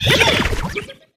Audio / SE / Cries / MAREANIE.ogg